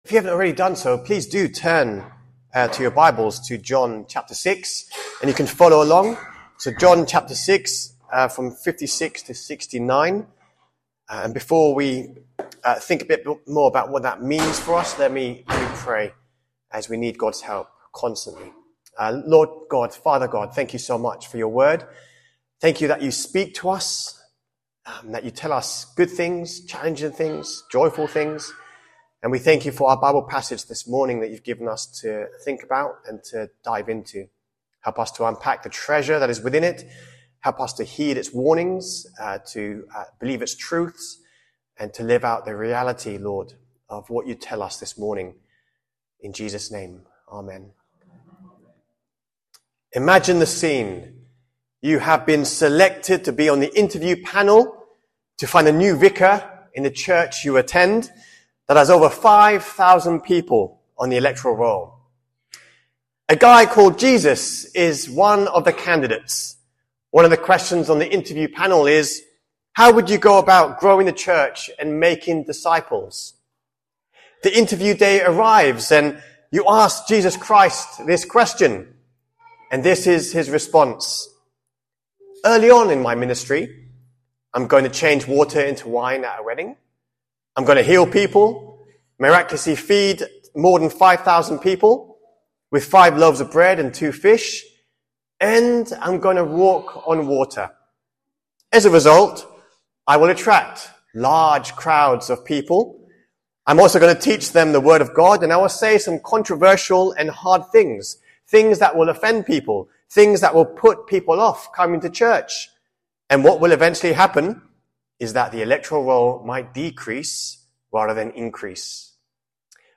sermon-25th-august.mp3